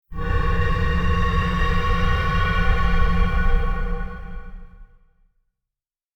Download Eerie sound effect for free.
Eerie